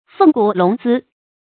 鳳骨龍姿 注音： ㄈㄥˋ ㄍㄨˇ ㄌㄨㄙˊ ㄗㄧ 讀音讀法： 意思解釋： 形容超凡的體格和儀態。